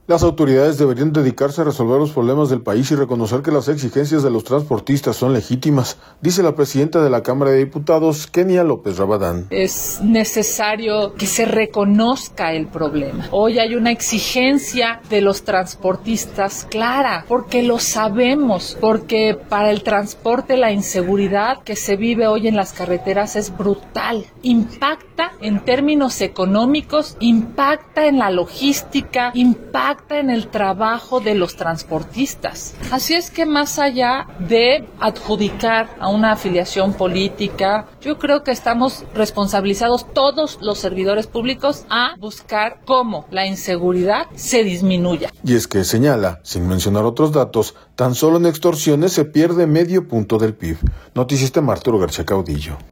Las autoridades deberían dedicarse a resolver los problemas del país y reconocer que las exigencias de los transportistas son legítimas, dice la presidenta de la Cámara de Diputados, Kenia López Rabadán.